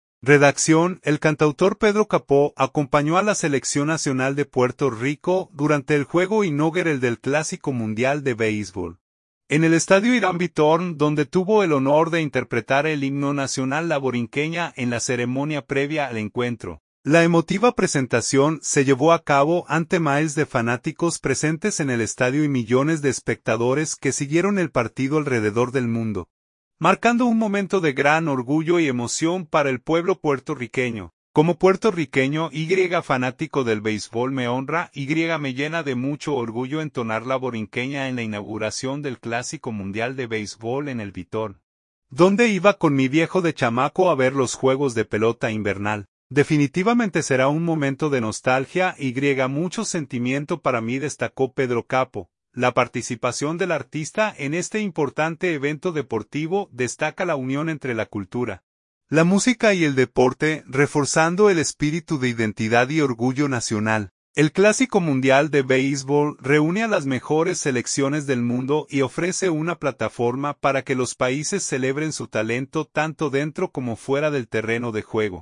Redacción.- El cantautor Pedro Capó acompañó a la selección nacional de Puerto Rico durante el juego inaugural del Clásico Mundial de Béisbol, en el Estadio Hiram Bithorn donde tuvo el honor de interpretar el himno nacional La Borinqueña en la ceremonia previa al encuentro.
La emotiva presentación se llevó a cabo ante miles de fanáticos presentes en el estadio y millones de espectadores que siguieron el partido alrededor del mundo, marcando un momento de gran orgullo y emoción para el pueblo puertorriqueño.